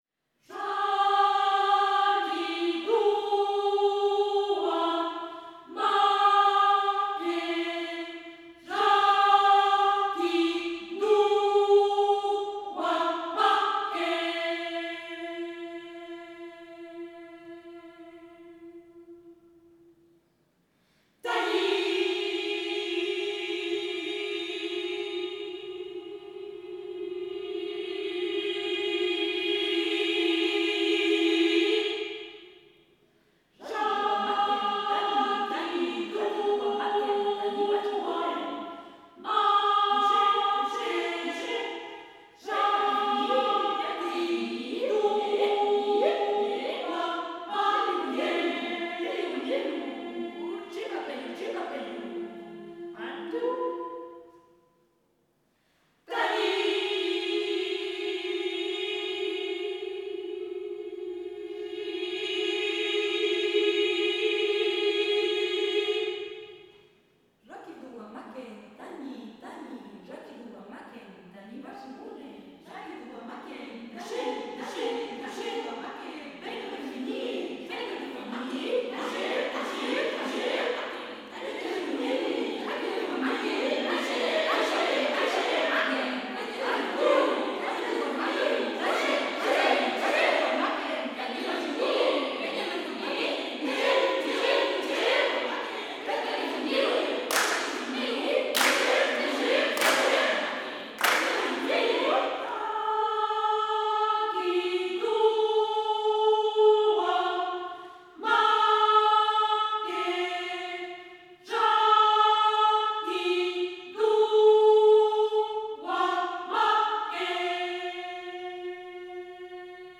Música vocal
Música tradicional